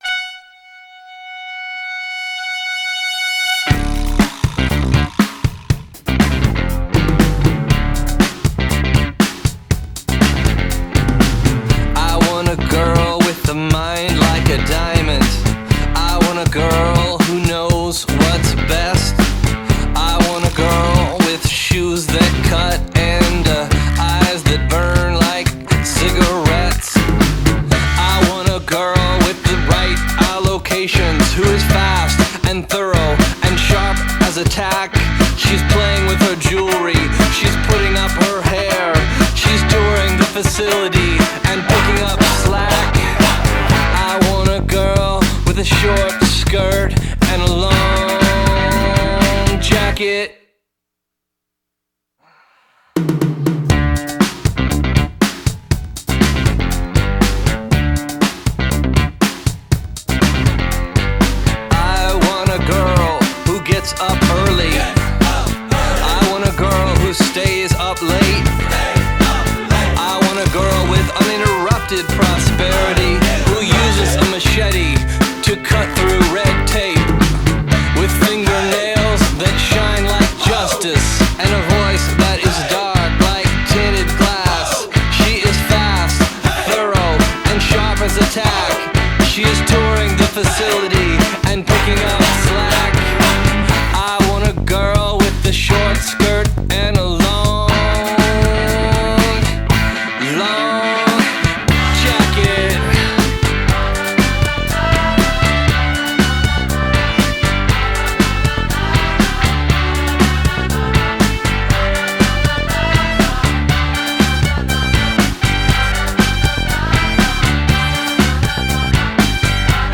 BPM120
Audio QualityMusic Cut
alt-rock